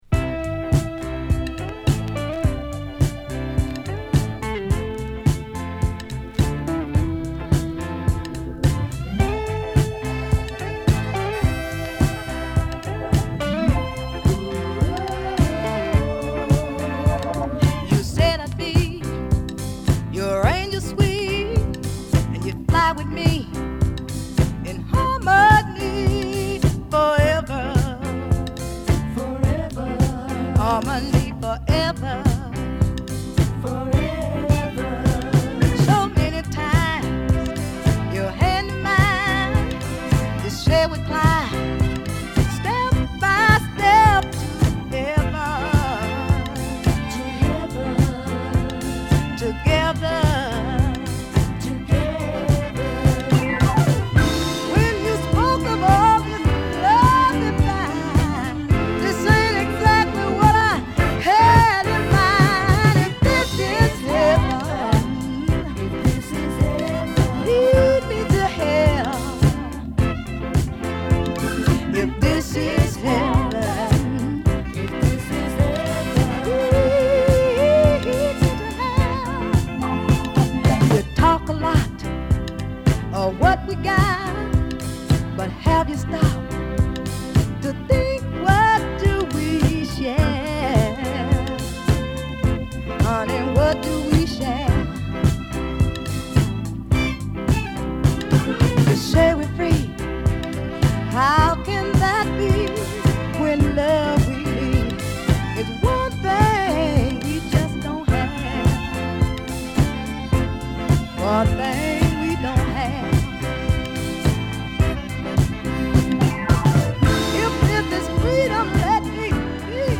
太いベースとシンプルなリズムにホーンやストリングスが絡むダンサブルな
晴れやかなミディアムソウル